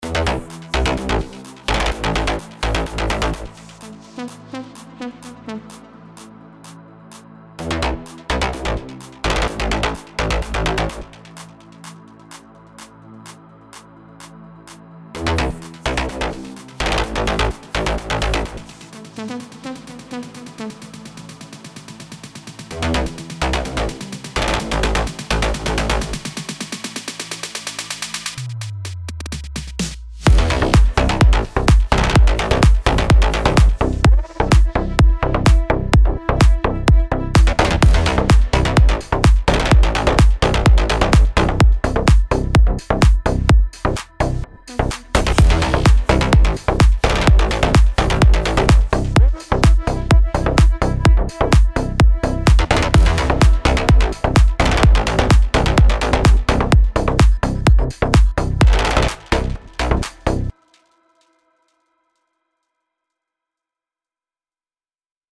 הכנתי טראק בסגנון טכנו
TECHNO.mp3